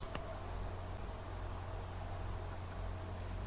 描述：老式西电500电话（实际上是ITT公司1965年的克隆产品）的三声铃声。 应用了轻微的降噪，否则没有处理。
Tag: 电话 电话 we500